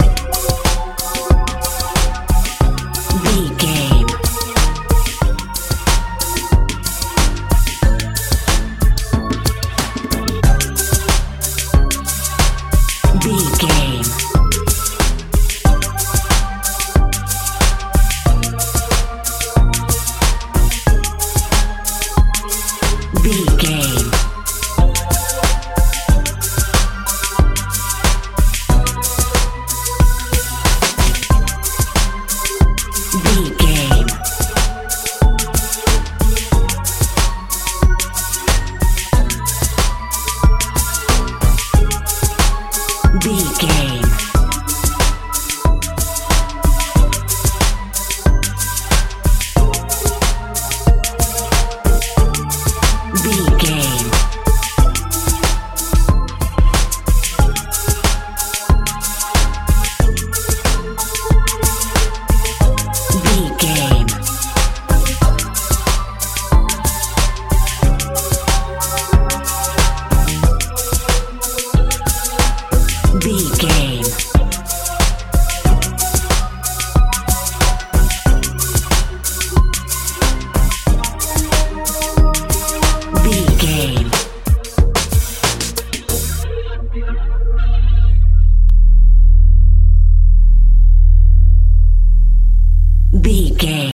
modern pop feel
Ionian/Major
F♯
mystical
magical
synthesiser
bass guitar
drums
strange